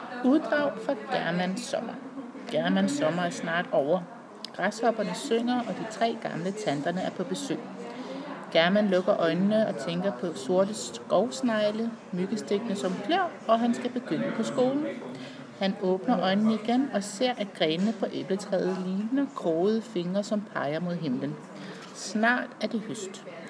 Oplæsning af "Garmanns Sommer".